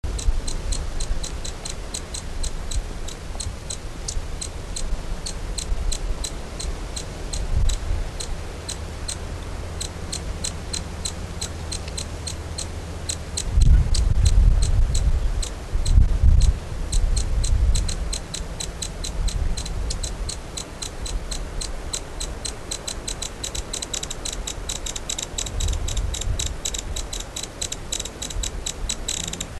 Amazilia versicolor
Calls  recorded Aguara Ñu, Mbaracayú Biosphere Reserve, Departamento Canindeyú